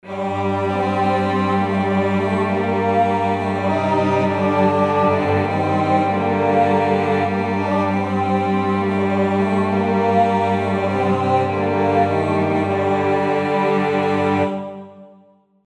До збірника увійшли народні лірницькі псальми Київщини, зібрані видатним українським фольклористом Порфирієм ДЕМУЦЬКИМ.
Комп’ютерне відтворення нот окремих пісень зі збірника: